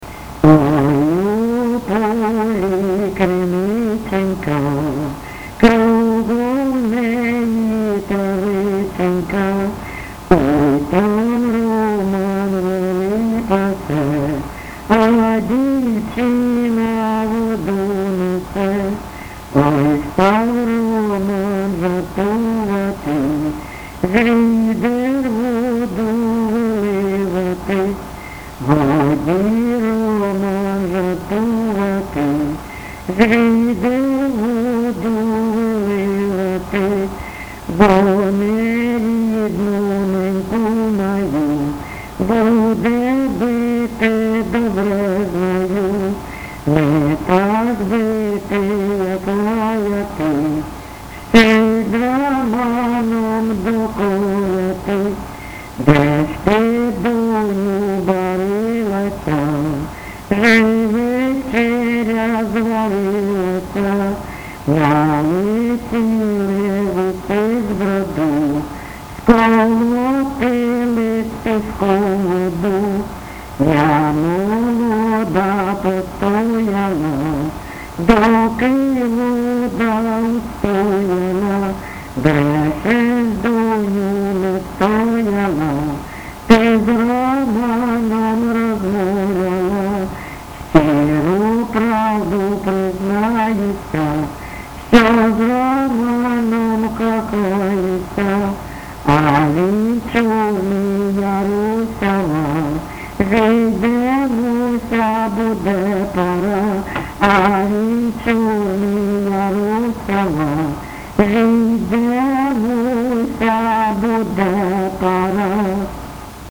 ЖанрПісні з особистого та родинного життя
Місце записус. Привілля, Словʼянський (Краматорський) район, Донецька обл., Україна, Слобожанщина